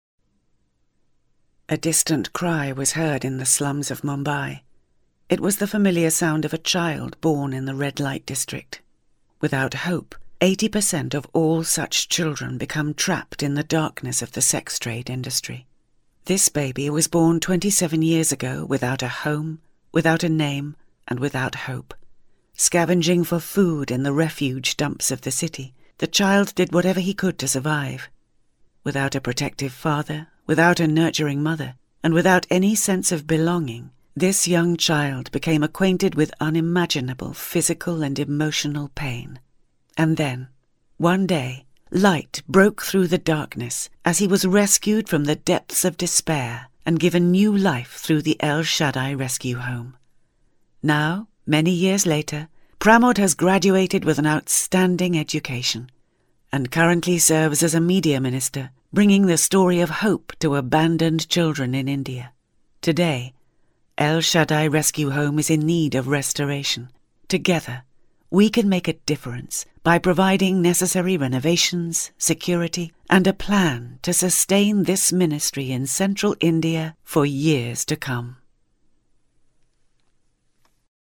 Female
English (British)
Adult (30-50), Older Sound (50+)
Modern mature standard English with smooth unforced authority; from newsreader-style informative to warm and reassuring - especially suitable for corporates, medical, educational and documentary style reads.
Television Spots
Charity Appeal